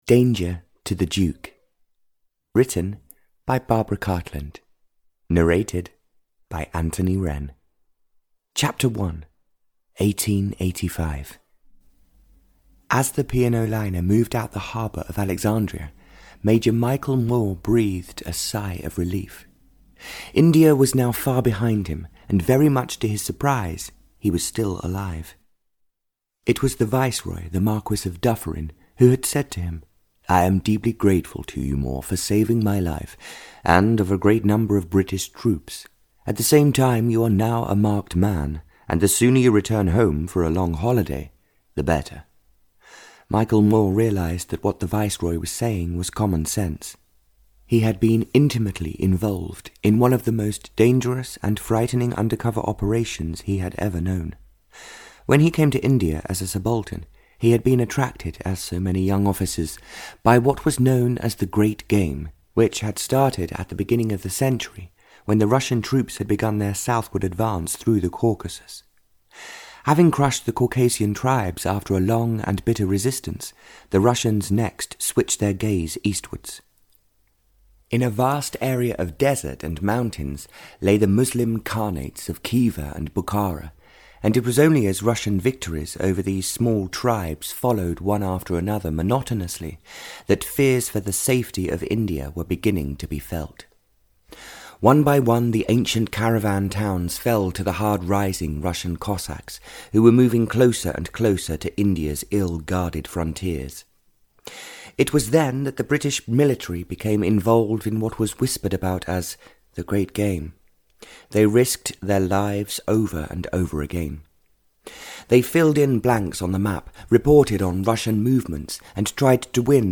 Danger to the Duke (EN) audiokniha
Ukázka z knihy